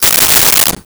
Silverware Movement 02
Silverware Movement 02.wav